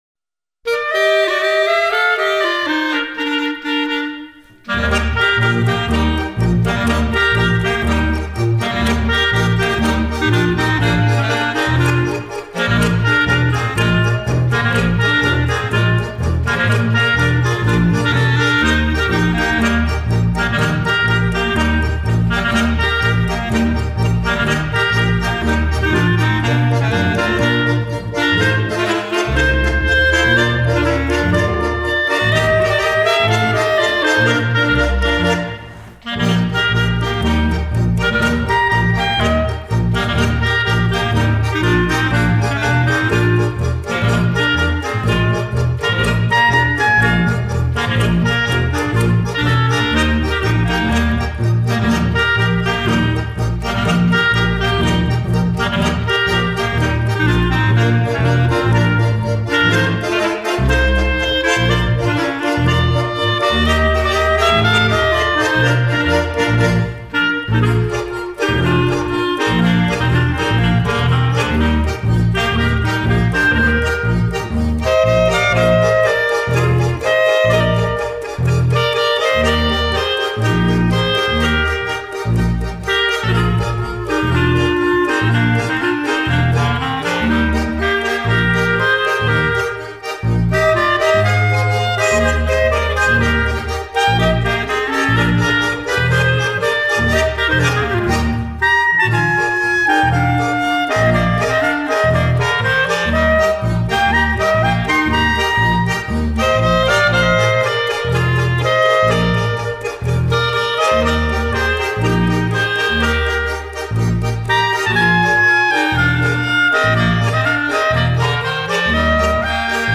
Marsch